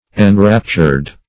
Enraptured - definition of Enraptured - synonyms, pronunciation, spelling from Free Dictionary